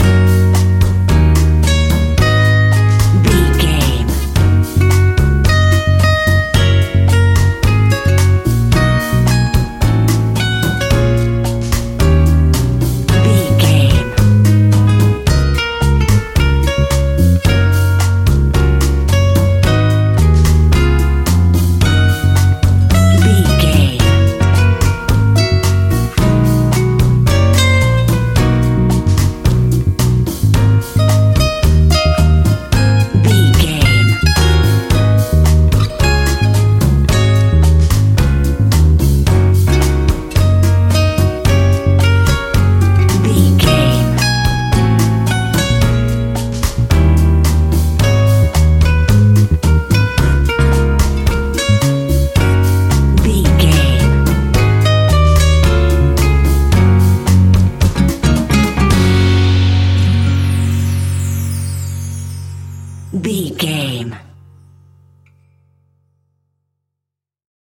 An exotic and colorful piece of Espanic and Latin music.
Ionian/Major
F#
romantic
maracas
percussion spanish guitar